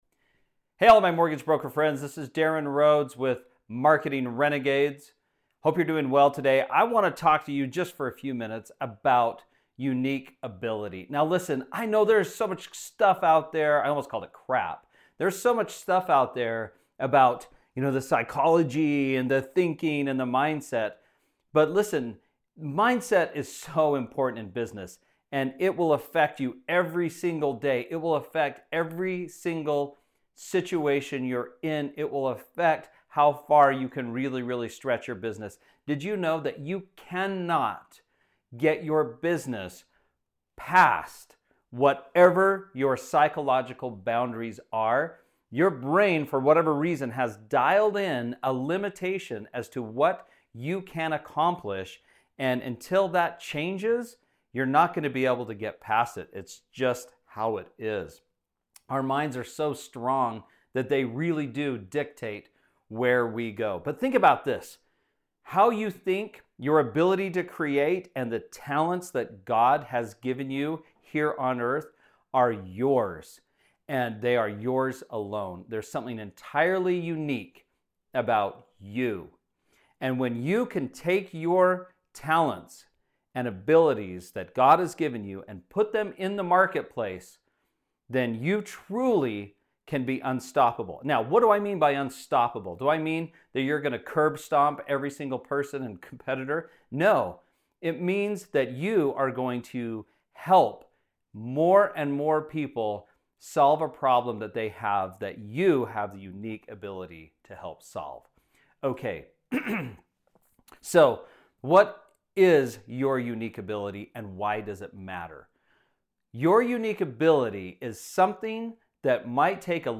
Livestream-Unique-Ability.mp3